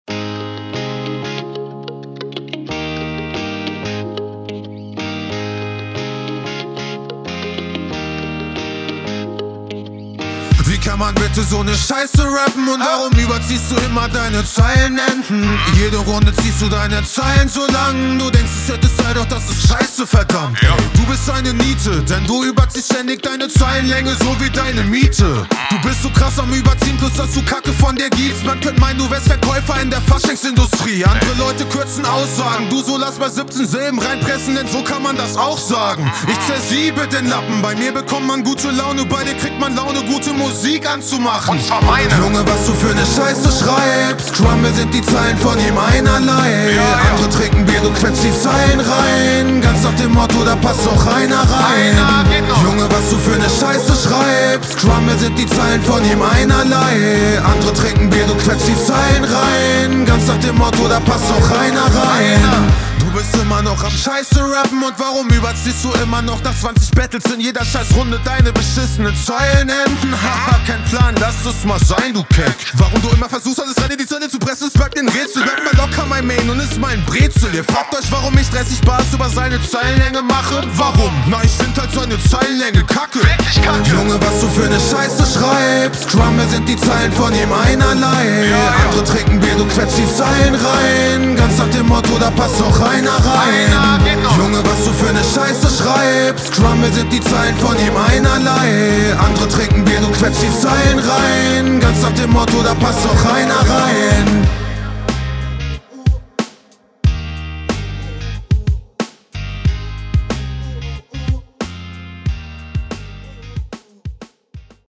Runde baut auf jeden Fall eine gute Atmosphäre auf und ist so ein guter Mix …